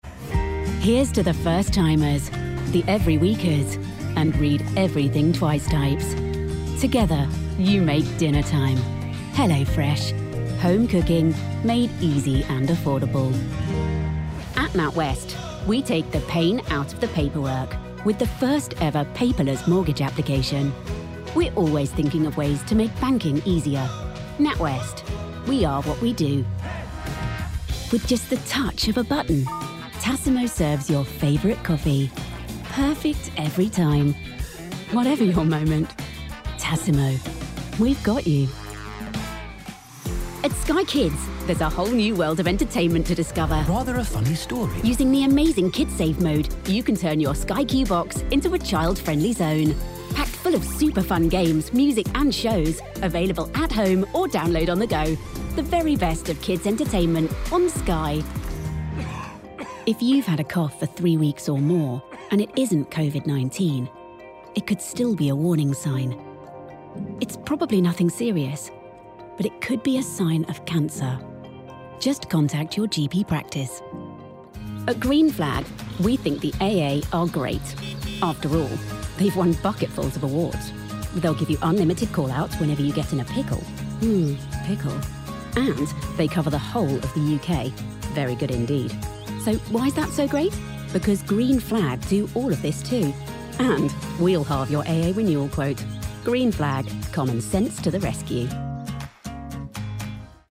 Inglés (Británico)
Natural, Versátil, Amable, Cálida, Empresarial
Comercial